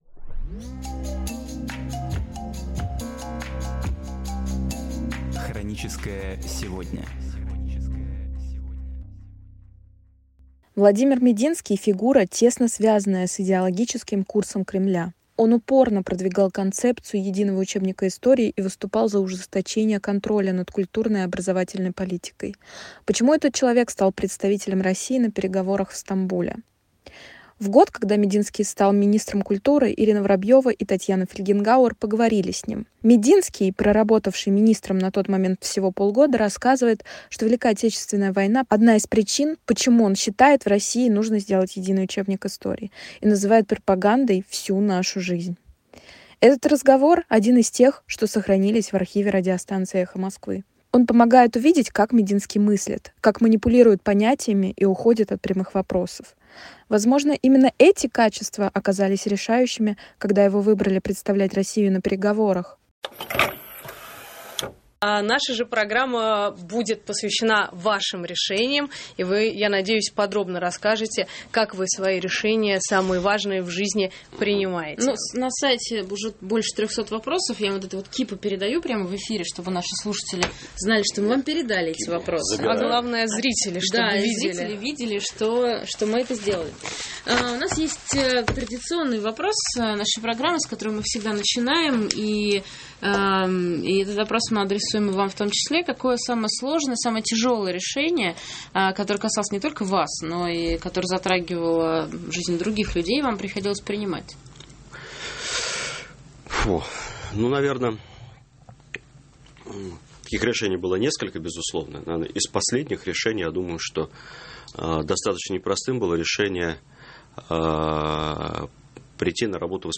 Архивные передачи «Эха Москвы» на самые важные темы дня сегодняшнего